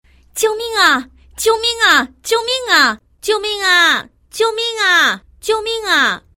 女生平淡语气喊救命音效免费音频素材下载